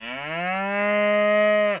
cow.wav